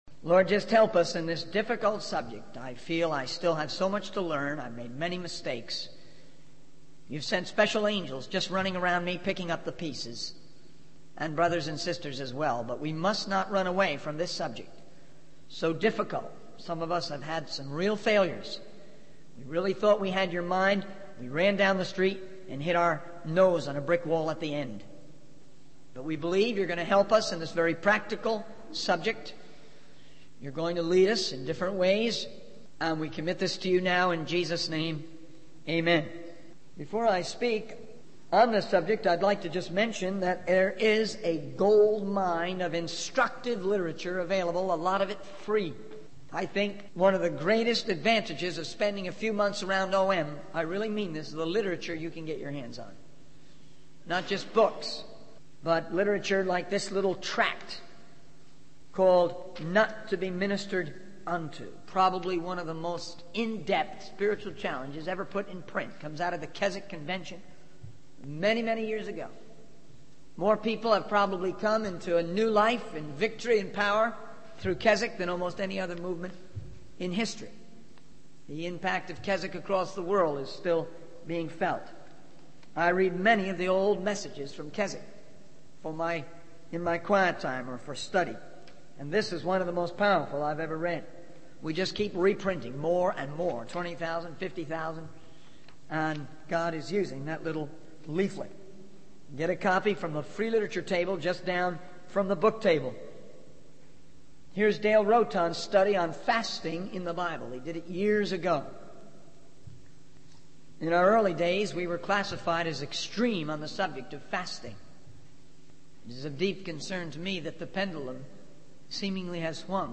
In this sermon, the speaker discusses the importance of making wise decisions and avoiding disastrous evaluations. They emphasize the need to find a balance in decision-making and provide practical principles to consider when making decisions.